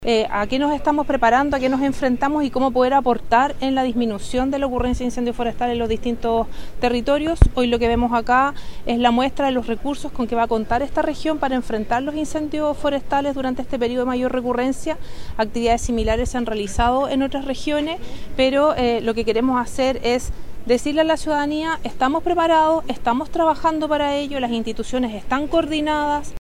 Por su parte, la directora nacional de Senapred, Alicia Cebrián, sostuvo que con estos recursos se le entrega un potente mensaje a la ciudadanía.